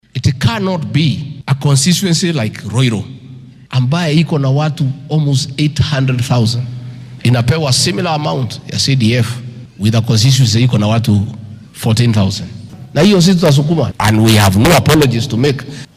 Xilli uu ku sugnaa magaalada Siakago ee ismaamulka Embu ayuu sheegay in nidaamkan uu muhiim u yahay horumarka gobolka Bartamaha wadanka. Waxaa uu tilmaamay in marka ay dadka tiradoodu badan tahay ay bixiyaan cashuur sare sidaasi awgeedna ay u xaq leeyihiin in la siiyo raasimaal u dhigma tiradooda.